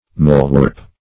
molewarp - definition of molewarp - synonyms, pronunciation, spelling from Free Dictionary Search Result for " molewarp" : The Collaborative International Dictionary of English v.0.48: Molewarp \Mole"warp`\, n. (Zool.)
molewarp.mp3